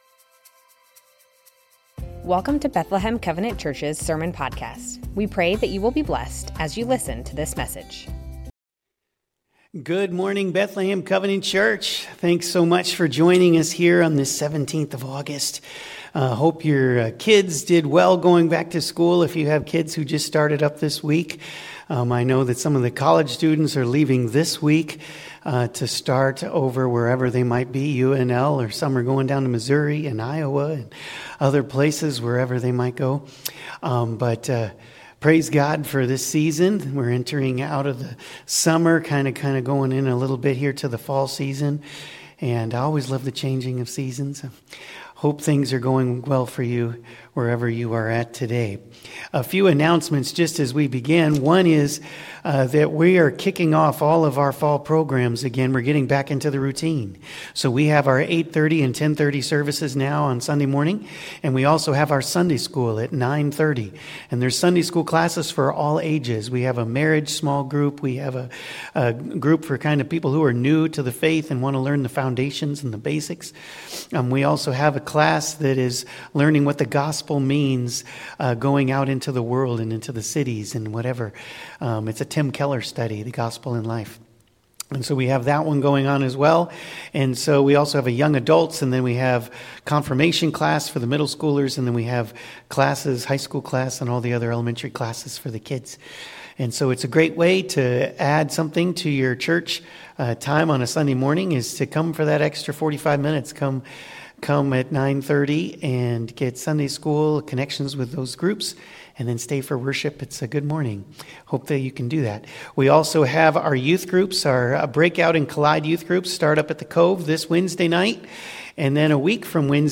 Bethlehem Covenant Church Sermons Names of God - Yahweh Aug 17 2025 | 00:34:14 1x 00:00 / 00:34:14 Subscribe Share Spotify RSS Feed Share Link Embed